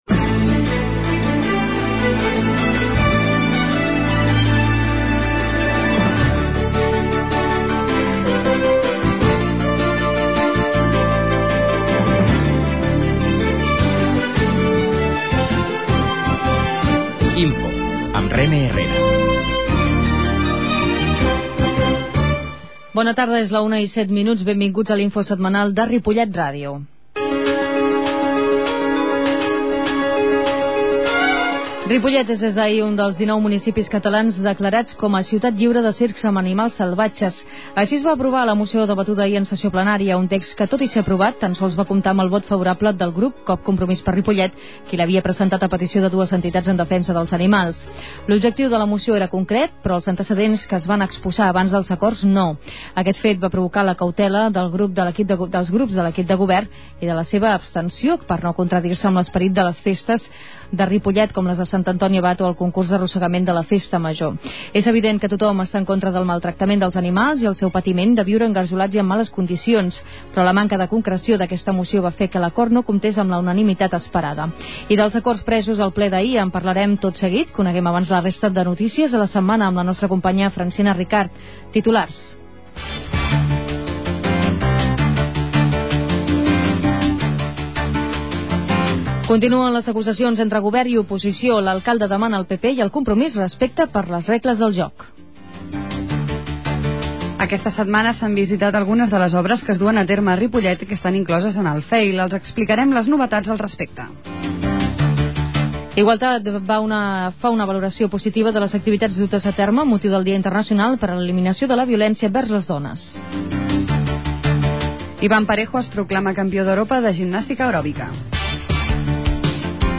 Redifusi� i desc�rrega per Internet Escolteu en directe per la r�dio o la xarxa el resum de not�cies de Ripollet R�dio (91.3 FM), que s'emet en directe a les 13 hores.
La qualitat de so ha estat redu�da per tal d'agilitzar la seva desc�rrega.